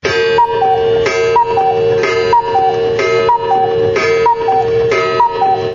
cuckoo clock
Tags: project personal sound effects